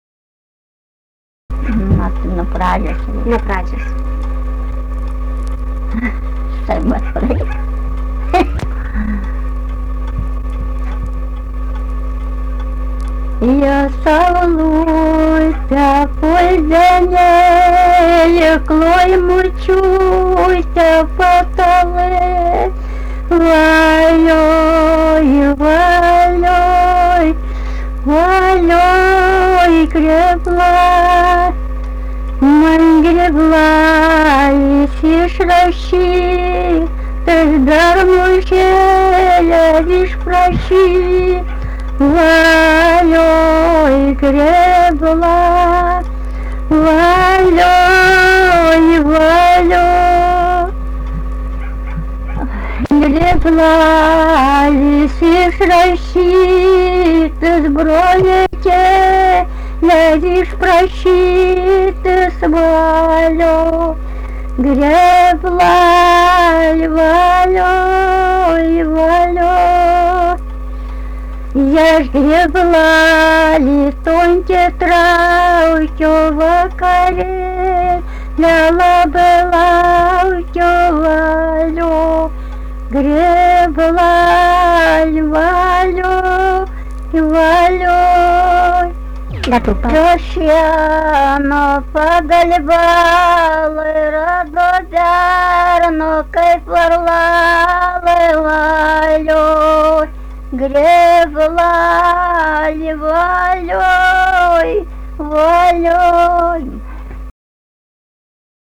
daina, kalendorinių apeigų ir darbo
Birželiai
vokalinis